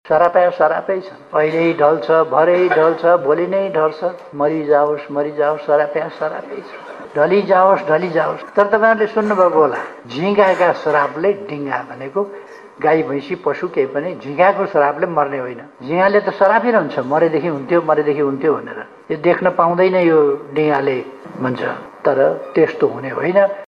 बुधवार नेपाल उत्पीडित जातीय मुक्ति समाजको ३७ औँ स्थापना दिवसका अवसरमा आयोजित कार्यक्रममा प्रधानमन्त्री ओलीले ‘झिँगाको सरापले डिँगा नमर्ने’ जवाफ दिए।